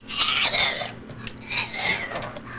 One of the most interesting things about Boyd is the sound he occasionally makes.